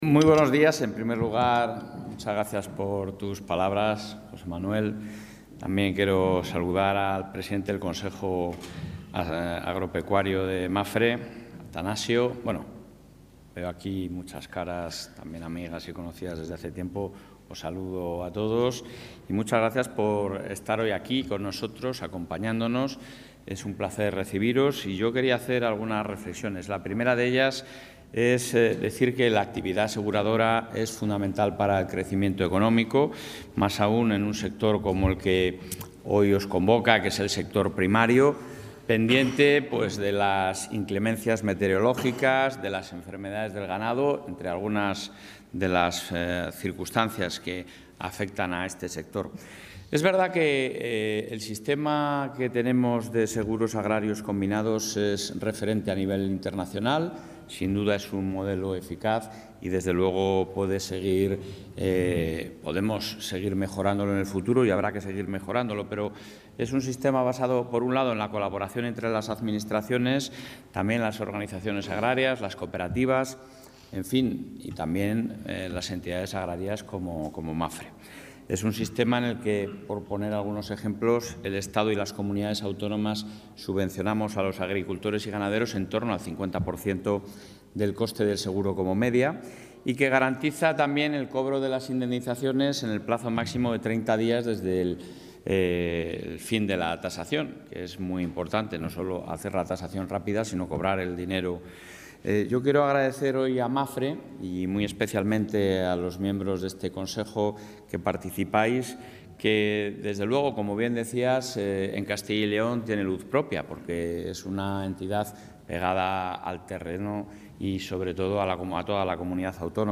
Intervención del presidente de la Junta.
El presidente autonómico ha mantenido un encuentro con el consejo asesor agropecuario del Grupo MAPFRE, donde ha anunciado nuevas medidas con el objetivo de que la Comunidad lidere en España el aumento de la cobertura de seguros agrarios